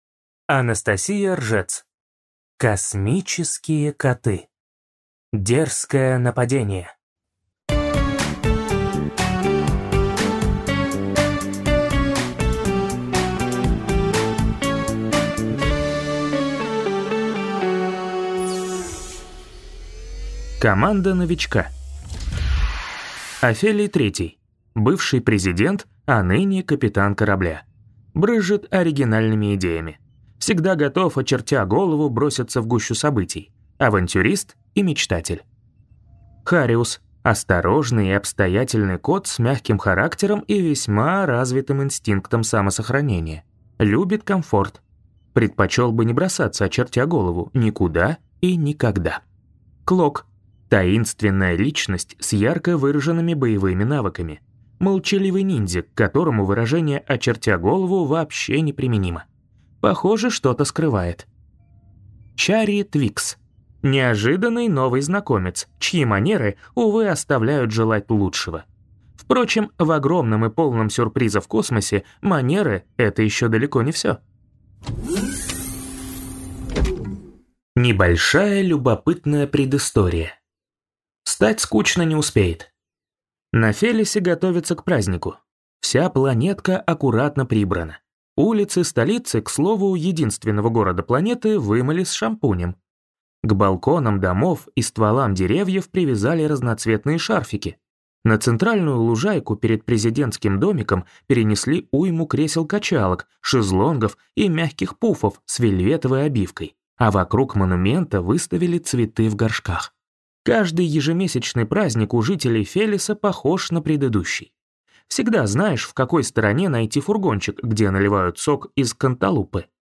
Аудиокнига Космические коты. Дерзкое нападение | Библиотека аудиокниг